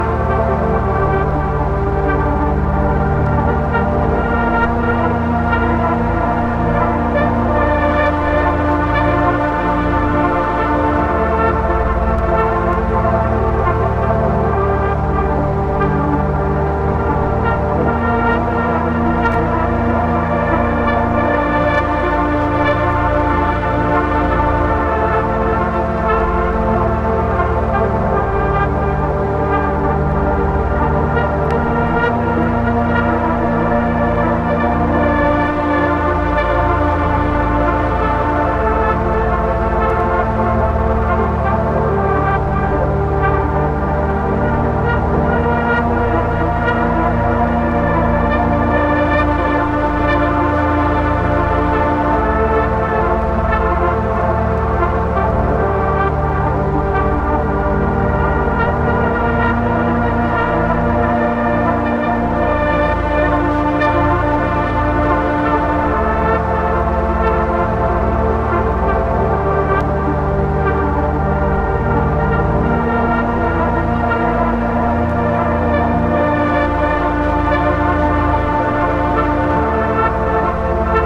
Ambient Electronica / Synth Soundscape